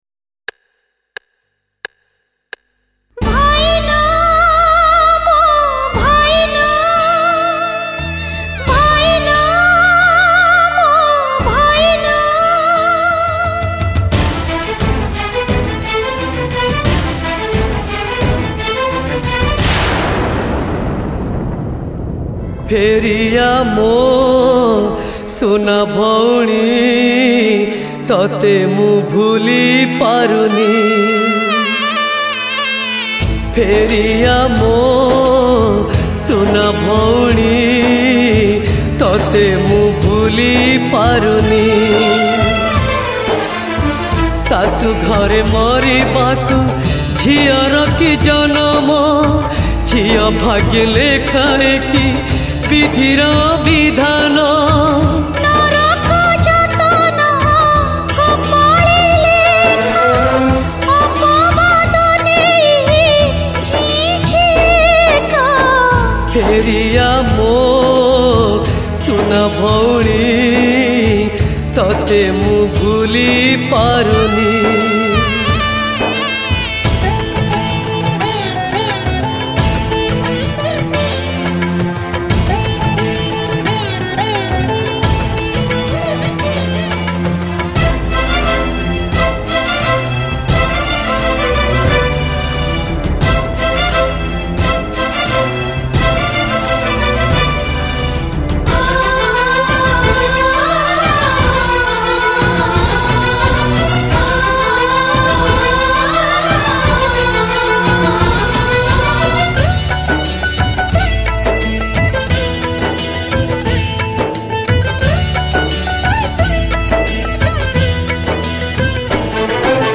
Sad Version